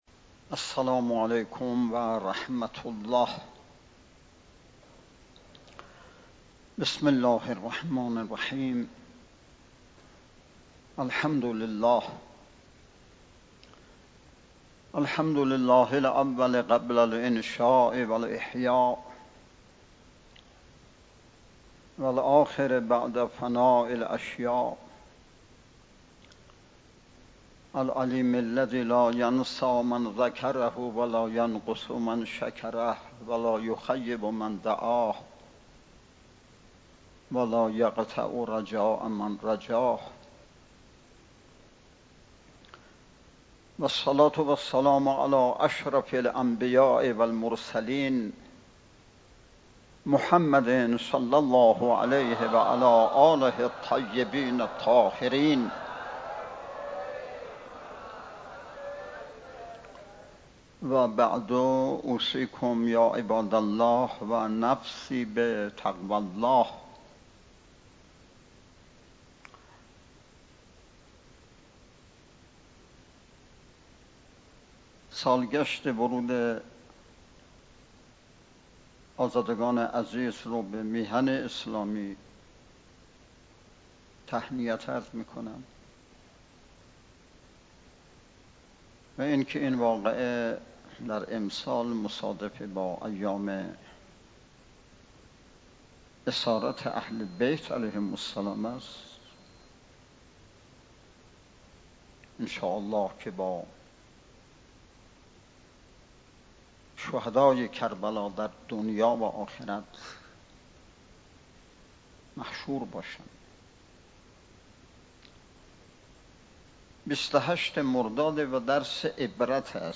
صوت / خطبه های نماز جمعه ۲۸ مردادماه ۱۴۰۱ بیرجند
صوت کامل خطبه های نماز جمعه ۲۸ مردادماه۱۴۰۱ بیرجند به امامت حجت الاسلام والمسلمین اقای حاج سید علیرضا عبادی نماینده ولی فقیه در خراسان جنوبی و امام جمعه بیرجندجهت استفاده عموم علاقه مندان منتشر شد.